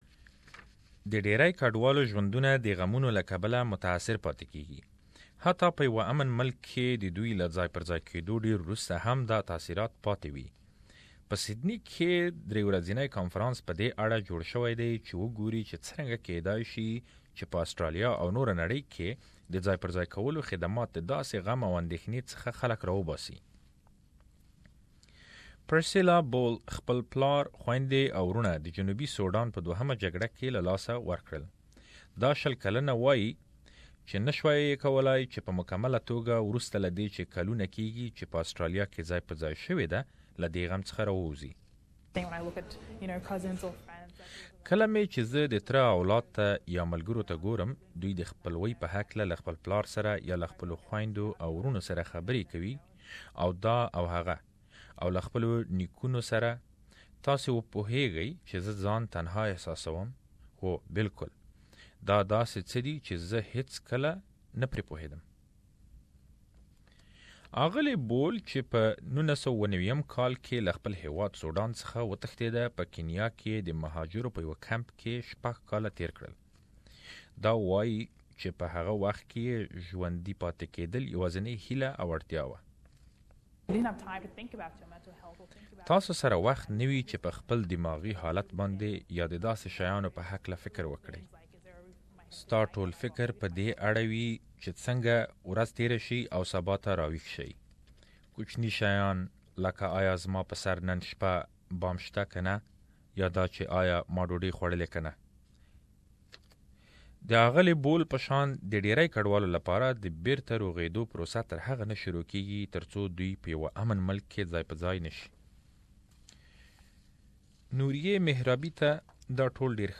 A three days conference in Sydney discusses awareness and preparedness for coping with trauma in the early stages of refugee settlement both in Australia and other countries. To know more, please listen to the report.